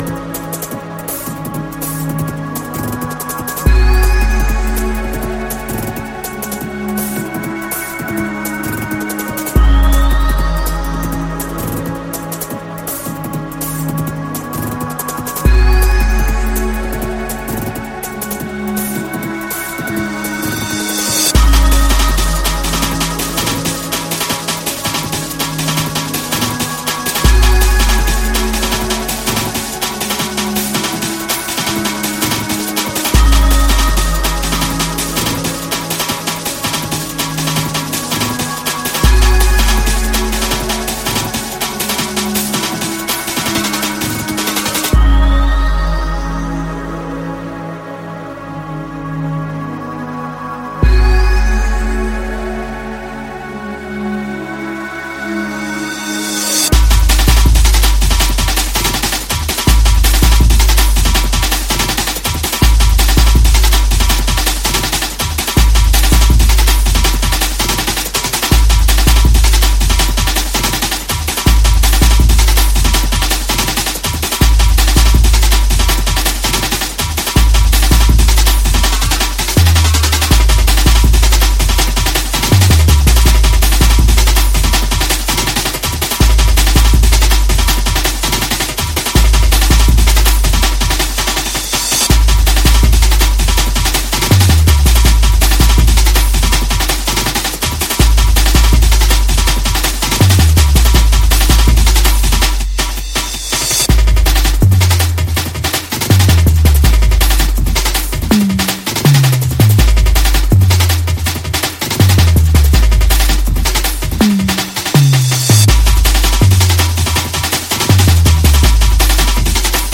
Jungle 12 Inch Single Reissue